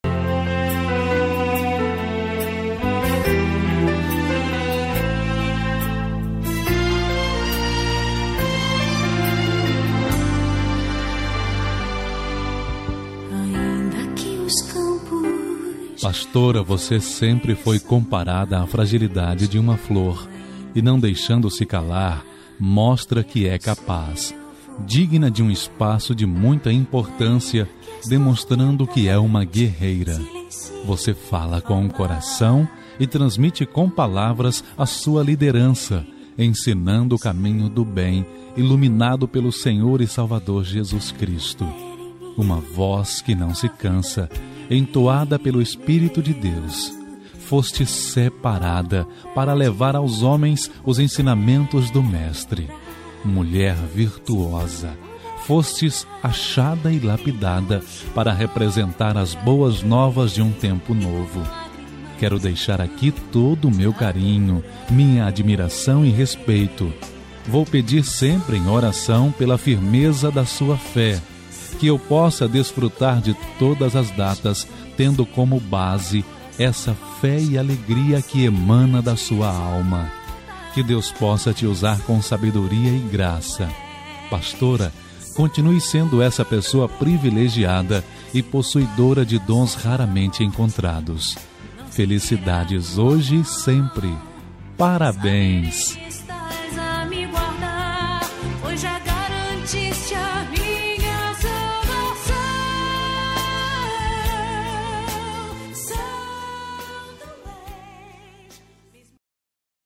Aniversário de Pastora – Voz Masculina – Cód: 5107